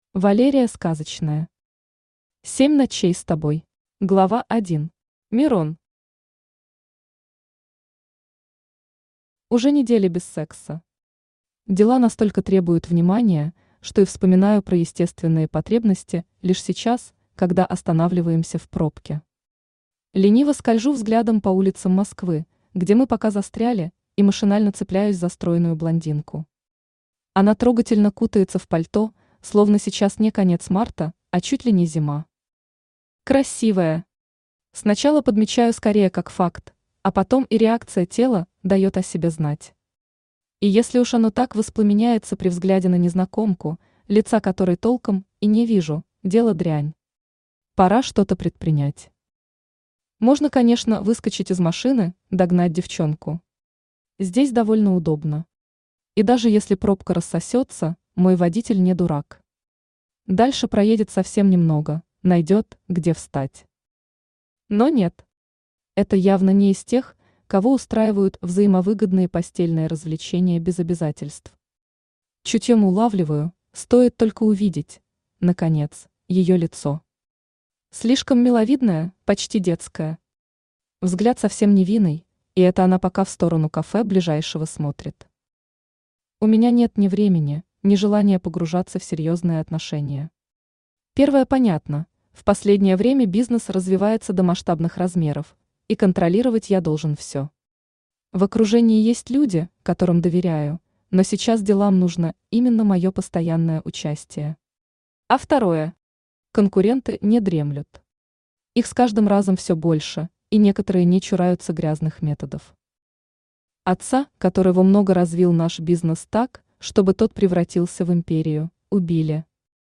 Aудиокнига Семь ночей с тобой Автор Валерия Сказочная Читает аудиокнигу Авточтец ЛитРес.